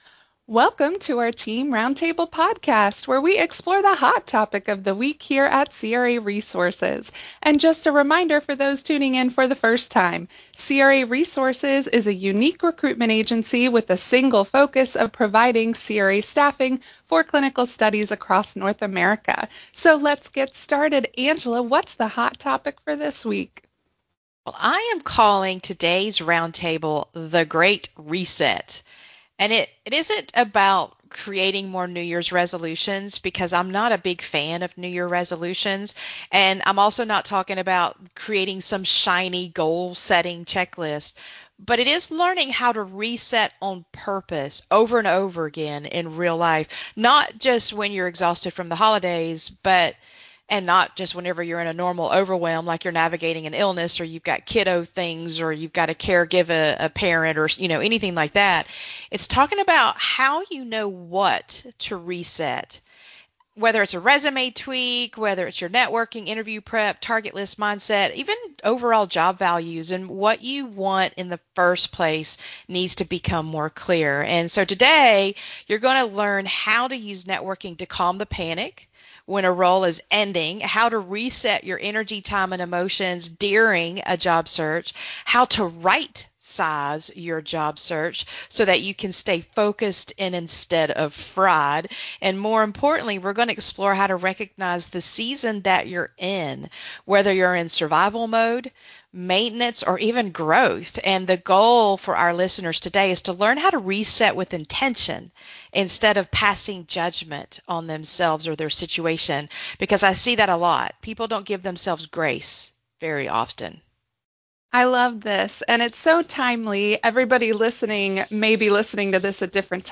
Roundtable: The Great Job Search Reset - craresources